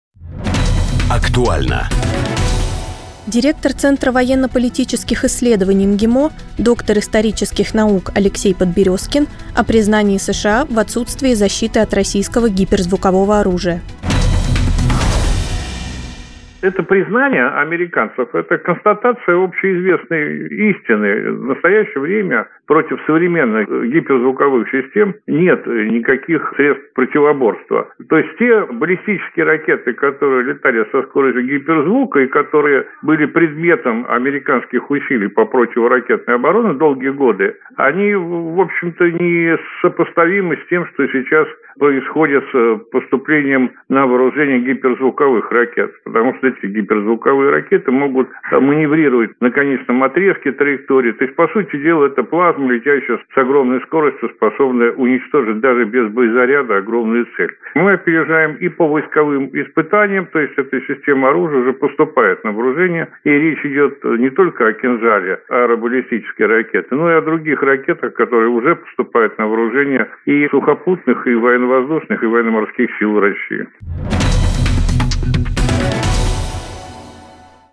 Источник: радио Sputnik